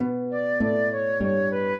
flute-harp
minuet12-3.wav